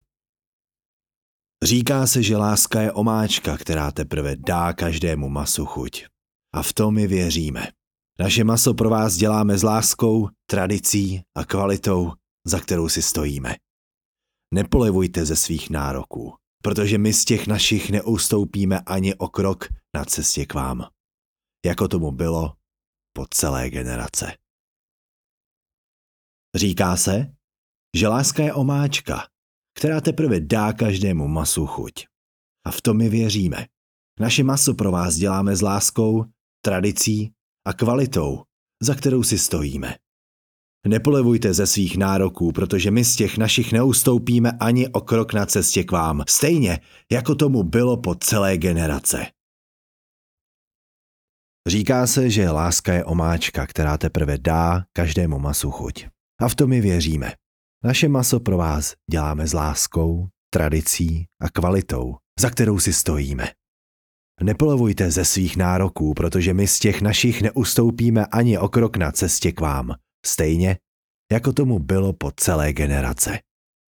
2min mužského hlasu pro produktová videa